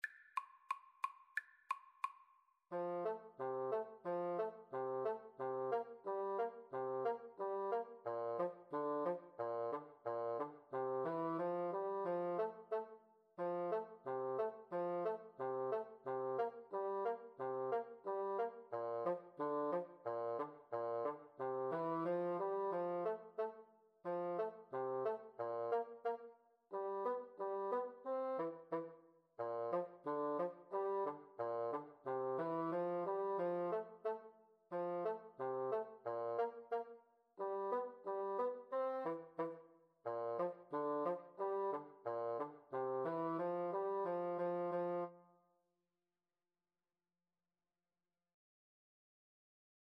2/2 (View more 2/2 Music)
F major (Sounding Pitch) (View more F major Music for French Horn-Bassoon Duet )
Traditional (View more Traditional French Horn-Bassoon Duet Music)